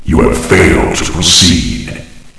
flak_m/sounds/announcer/int/failed.ogg at efc08c3d1633b478afbfe5c214bbab017949b51b